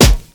Royality free snare single hit tuned to the F# note. Loudest frequency: 1277Hz
• '00s Rich Lows Hip-Hop Steel Snare Drum F# Key 45.wav
00s-rich-lows-hip-hop-steel-snare-drum-f-sharp-key-45-8Km.wav